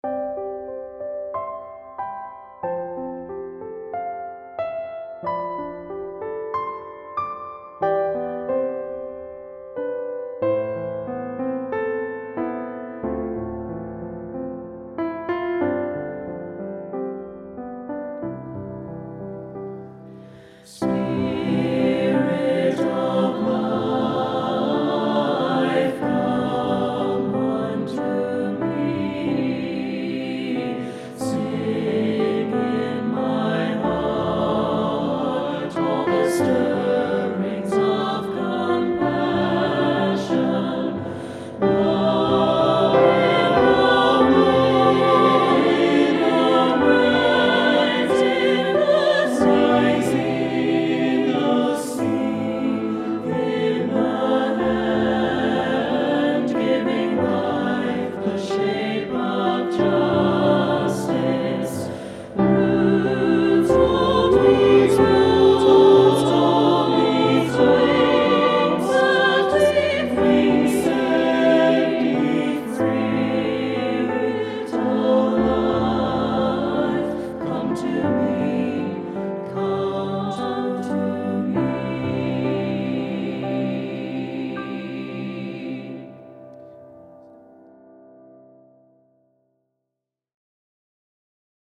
for SATB voices and piano